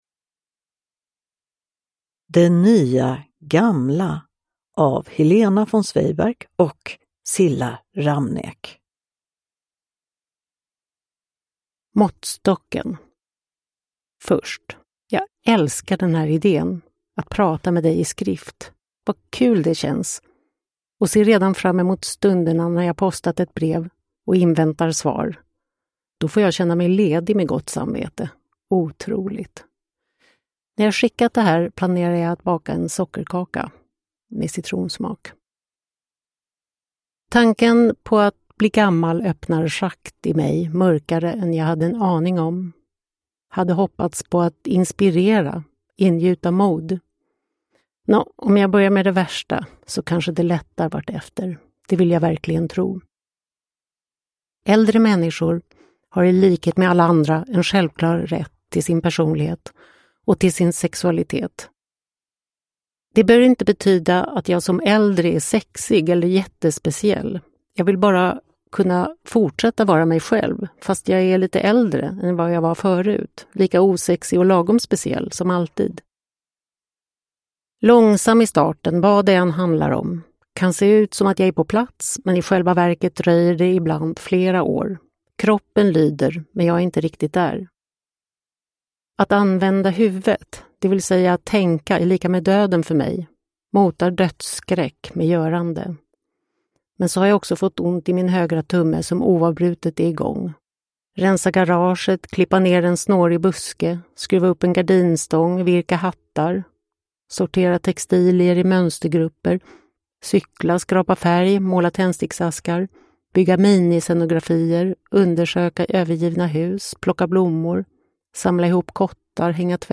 Det nya gamla : Sökord: kvinna Bakgrund: åldrande Tillstånd: vilsen – Ljudbok
Uppläsare: Helena von Zweigbergk, Cilla Ramnek